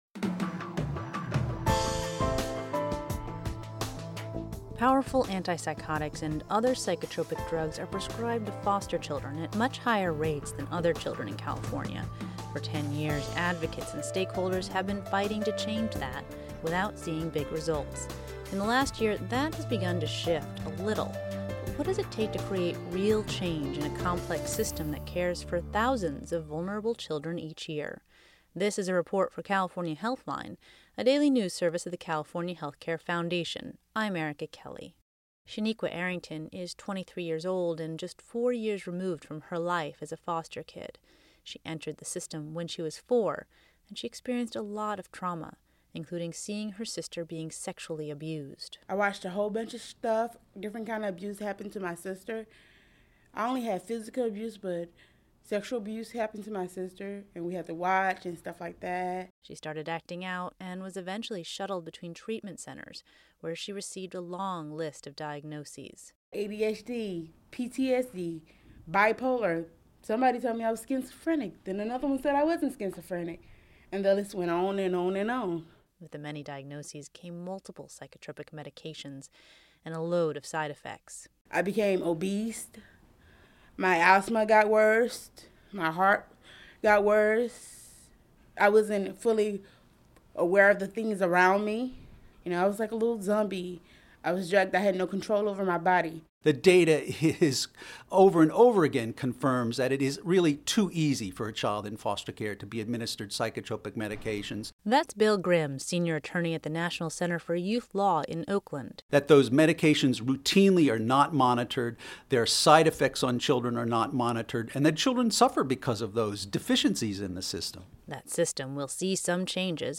• State Sen. Jim Beall (D-San Jose);
Audio Report Insight Multimedia